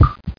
00081_Sound_pump.mp3